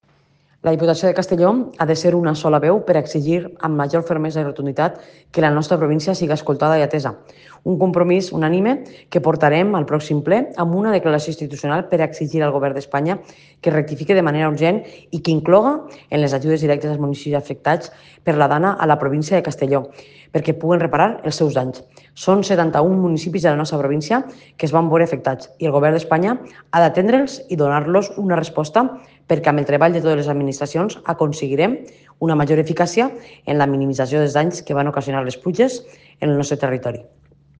Presidenta-Marta-Barrachina-Declaracion-Institucional.mp3